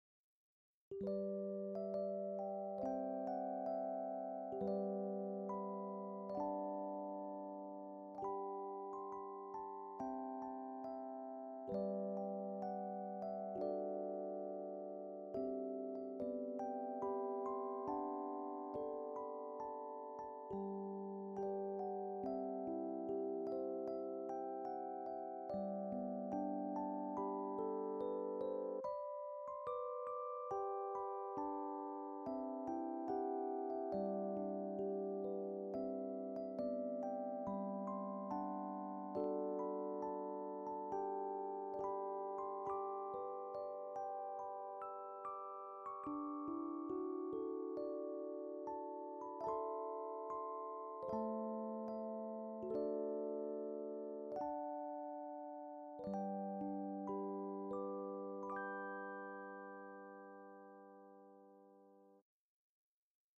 エレクトーンバージョン
旧新十津川小学校校歌 エレクトーン演奏